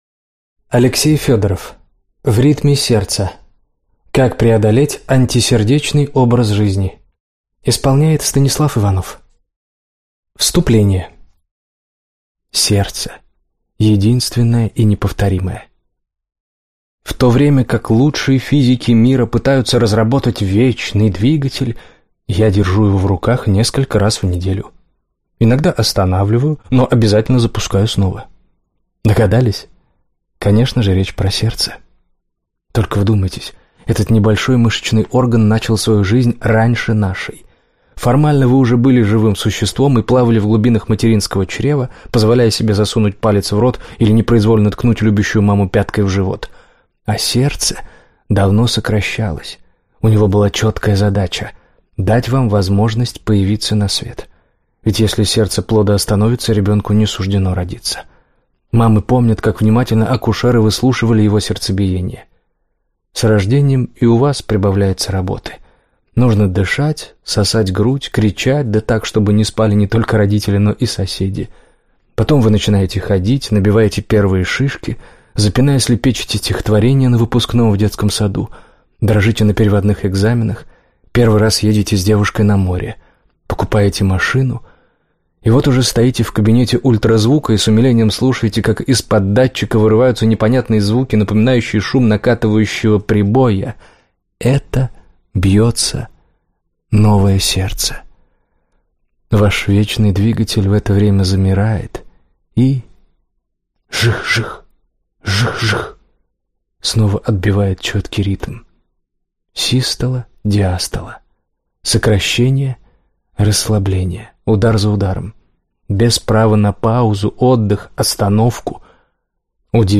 Аудиокнига В ритме сердца! Как преодолеть антисердечный образ жизни | Библиотека аудиокниг